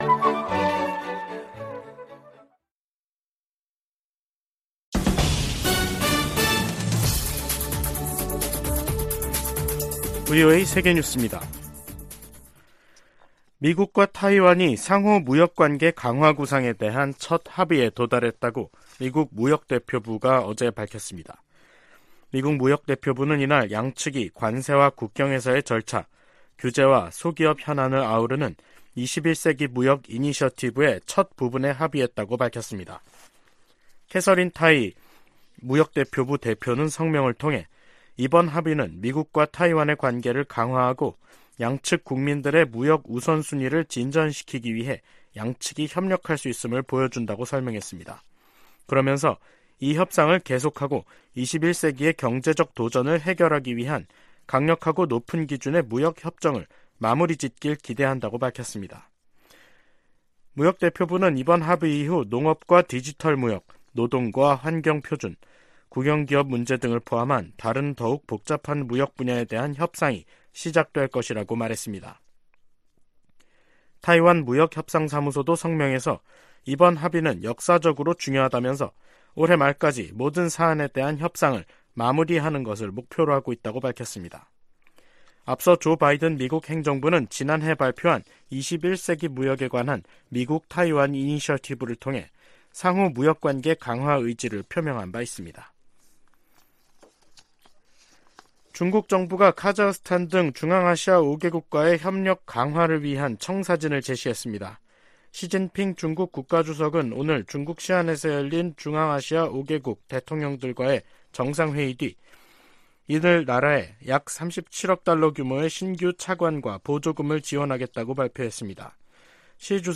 VOA 한국어 간판 뉴스 프로그램 '뉴스 투데이', 2023년 5월 19일 2부 방송입니다. 미국과 일본 정상이 히로시마에서 회담하고 북한의 핵과 미사일 문제 등 국제 현안을 논의했습니다. 윤석열 한국 대통령이 19일 일본 히로시마에 도착해 주요7개국(G7) 정상회의 참가 일정을 시작했습니다. 북한이 동창리 서해발사장에 새로짓고 있는 발사대에서 고체연료 로켓을 시험발사할 가능성이 높다고 미국 전문가가 분석했습니다.